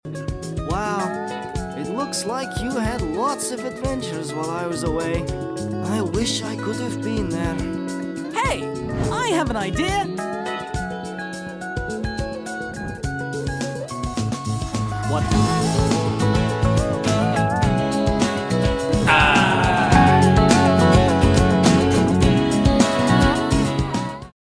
speed up version